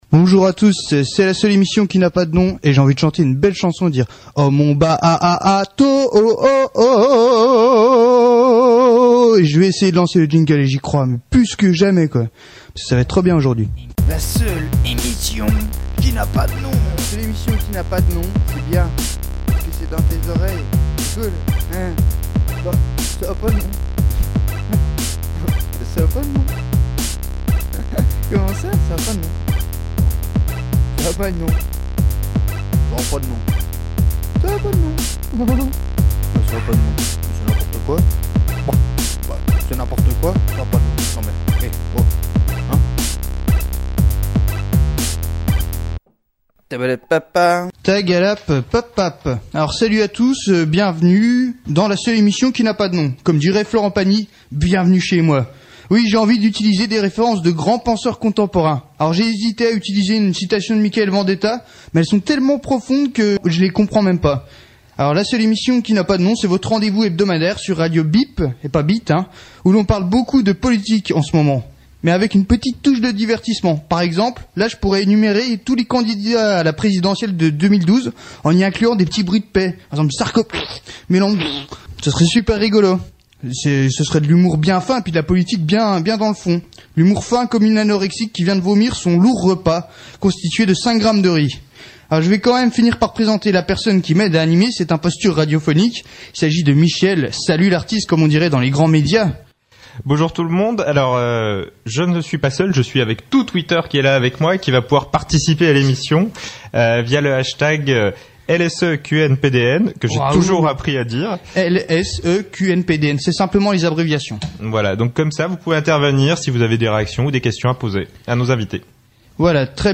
Podcast du débat